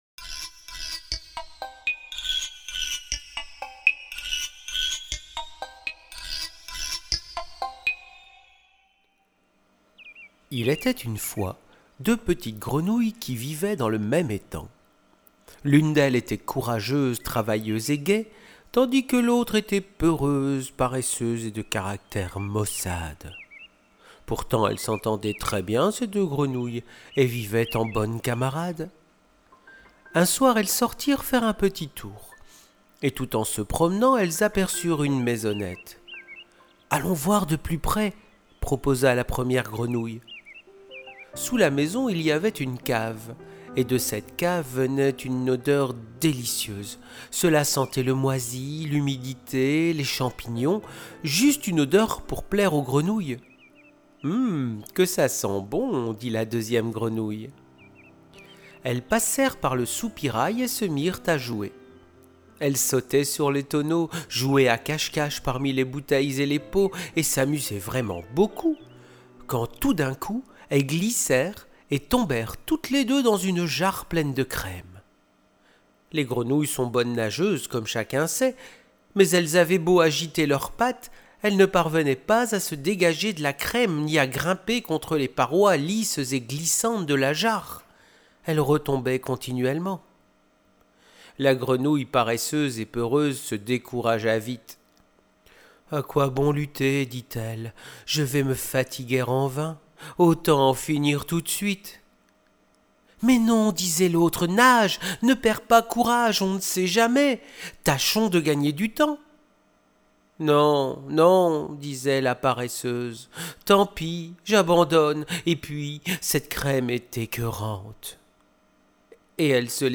Conte : « Les 2 grenouillles »